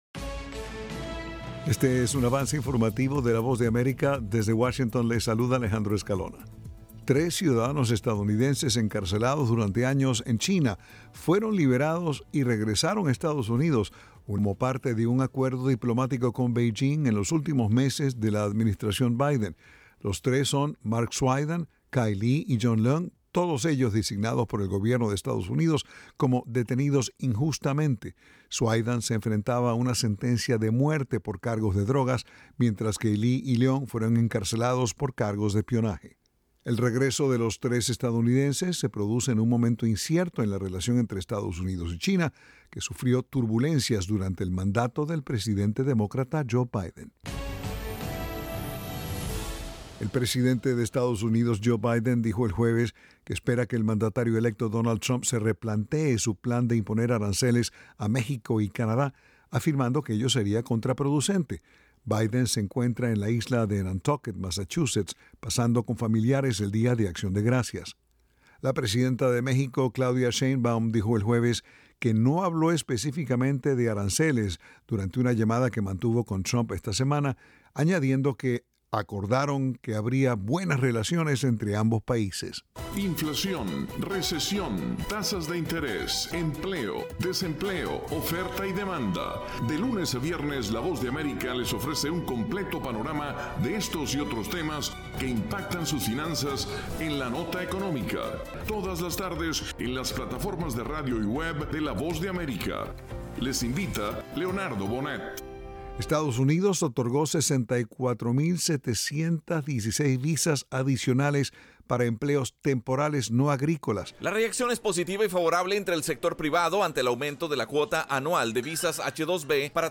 Avance Informativo
Este es un avance informativo de la Voz de América.